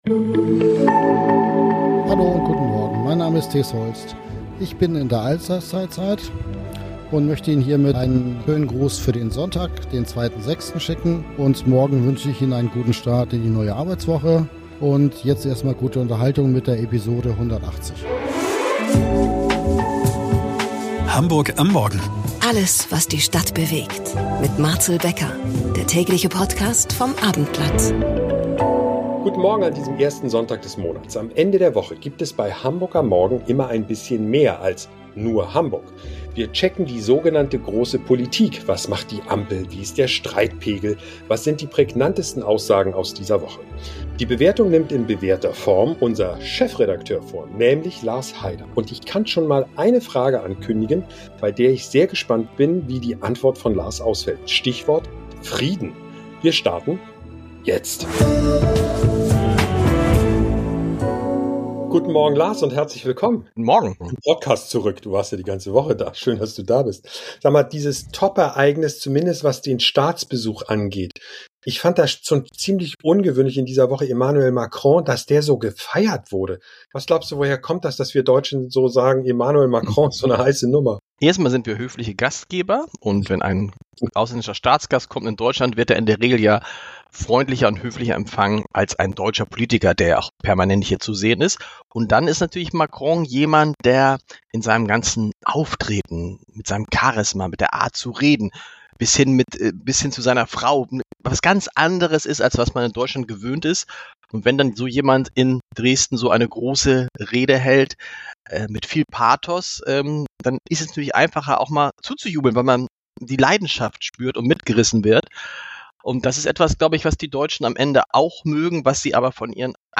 - Ist der Kanzler ein krasser Rechthaber? - Robert Habeck und sein Experiment „Heizungsgesetz“ - Nach unserer Aufzeichnung - Strack-Zimmermann entschuldigt sich bei Autisten für ihren Vergleich - Sorry für unsere Tonprobleme!